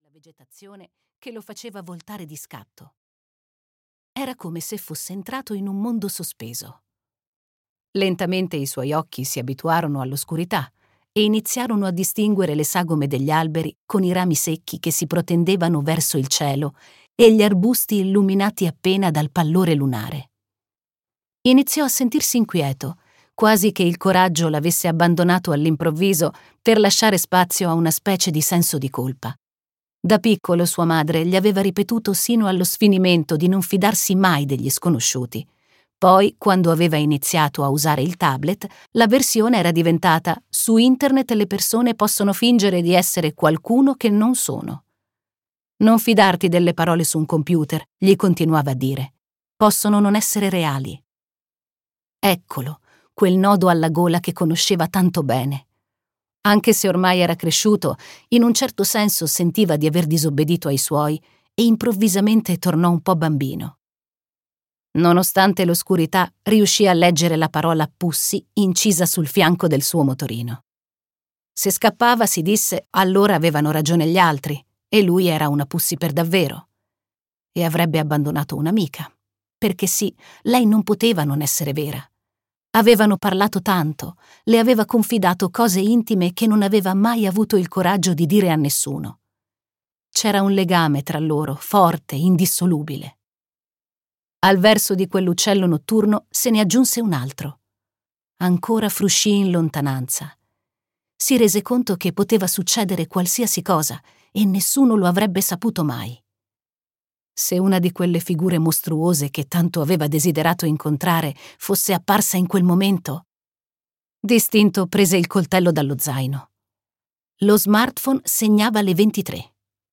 "Anna non dimentica" di Adriano Giotti - Audiolibro digitale - AUDIOLIBRI LIQUIDI - Il Libraio